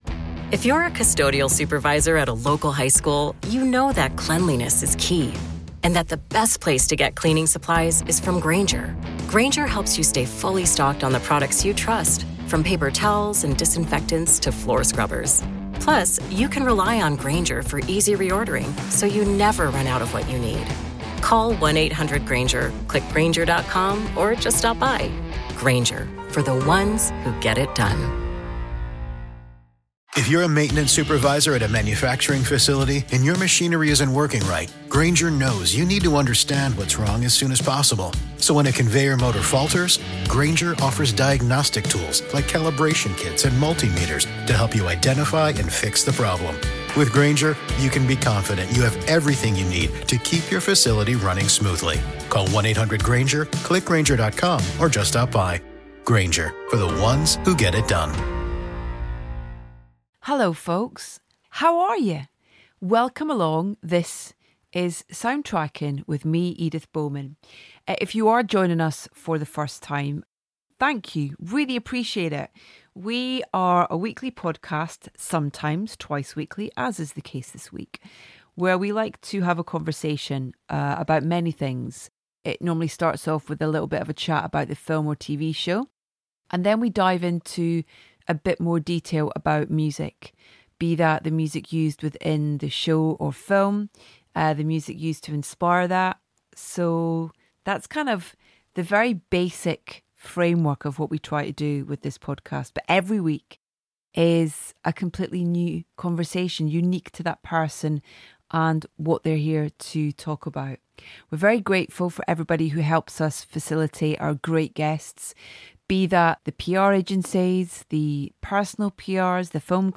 Our latest guest on Soundtracking is Shannon Murphy, director of the wonderful feature film Babyteeth, and TV shows like Killing Eve and Dope Girls to name but a few. Shannon joins us to discuss Dying For Sex, which you can watch now on Disney+.